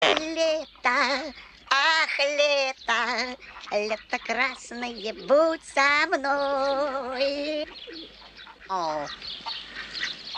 • Качество: 192, Stereo
веселые